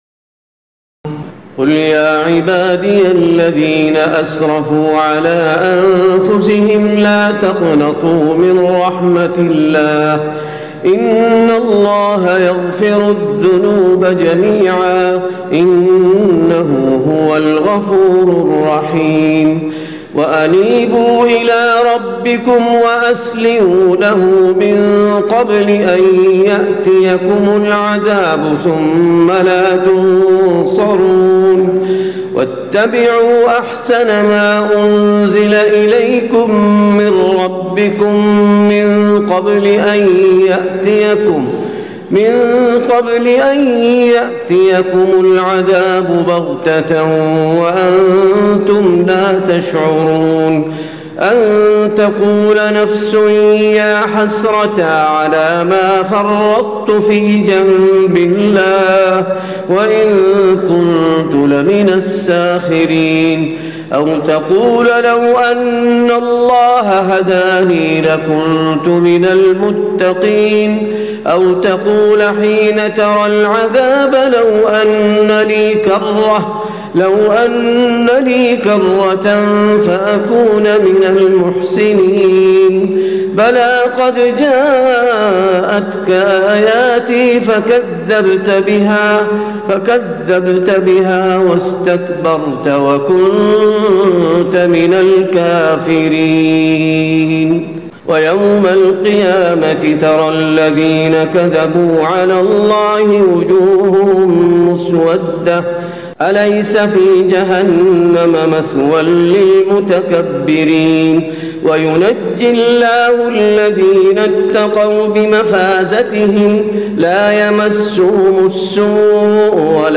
تراويح ليلة 23 رمضان 1436هـ من سورة الزمر (53-75) Taraweeh 23 st night Ramadan 1436H from Surah Az-Zumar > تراويح الحرم النبوي عام 1436 🕌 > التراويح - تلاوات الحرمين